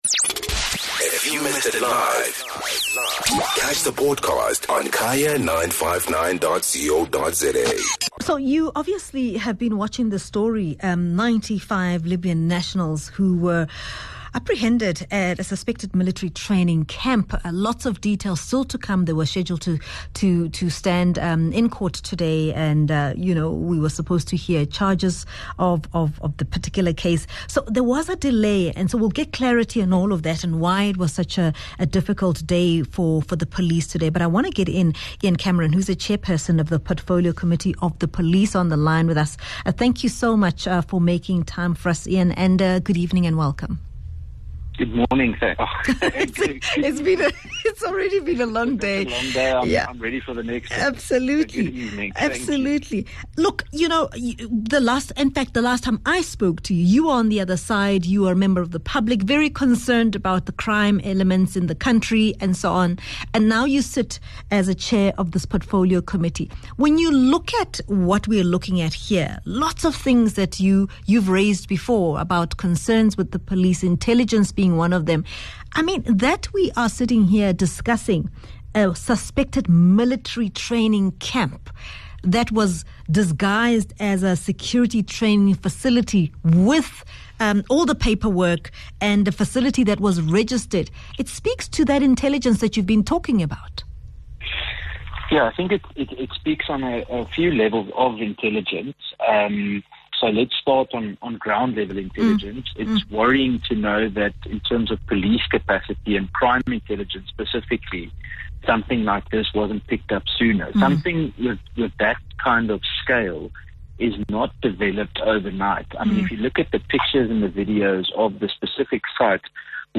Guest: Ian Cameron - Chairperson of Portfolio Committee on Police